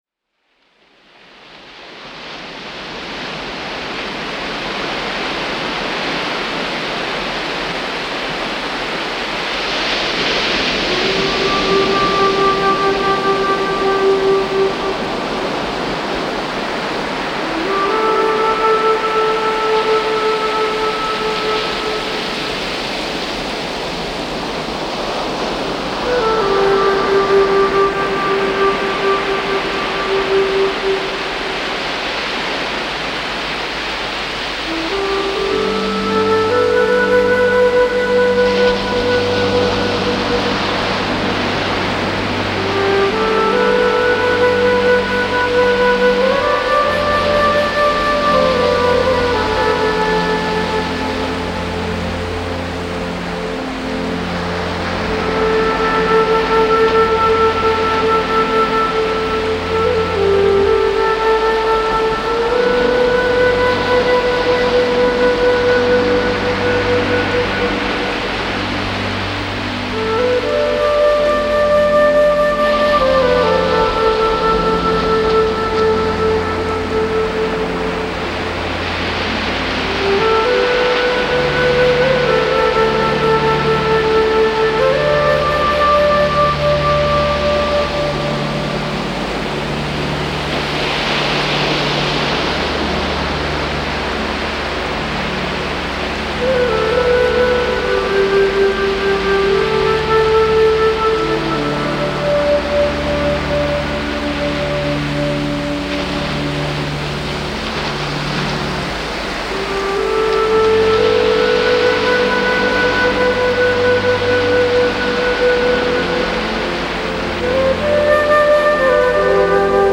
flute improvisations